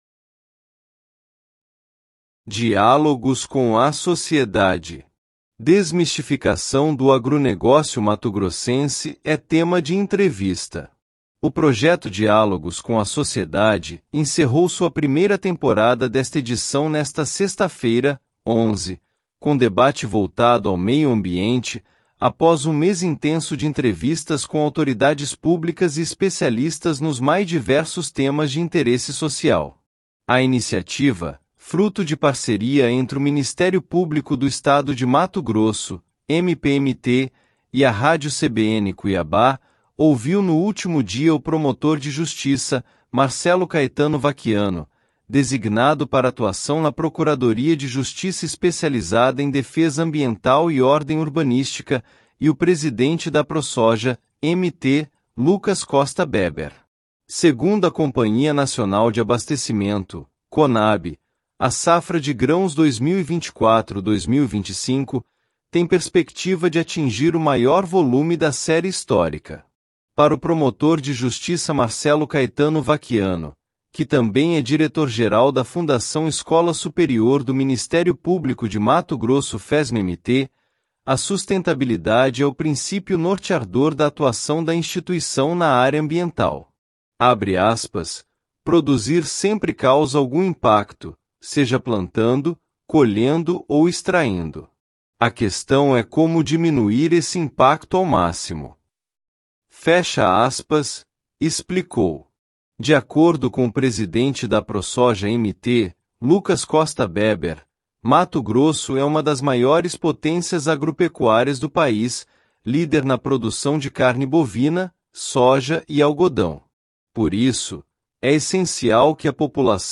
Desmistificação do agronegócio mato-grossense é tema de entrevista
Desmistificação do agronegócio mato-grossense é tema de entrevista_ mp3.mp3